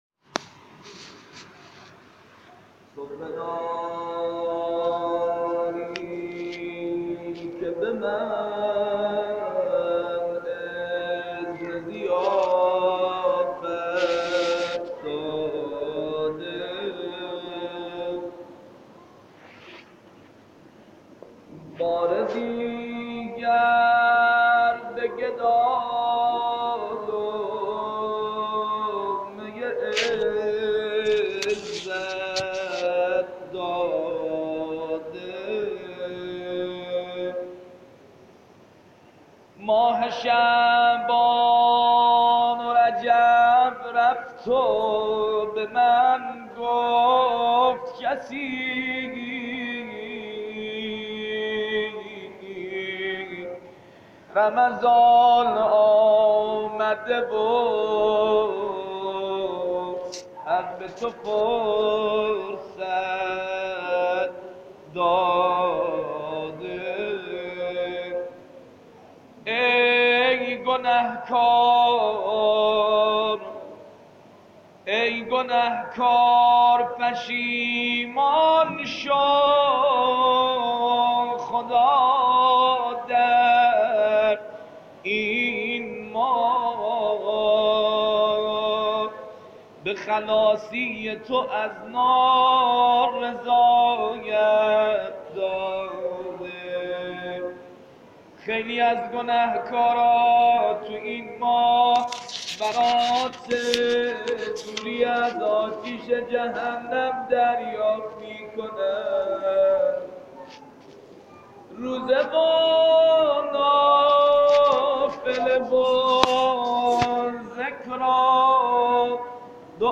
اولین مراسم دعای کمیل درماه مبارک رمضان2017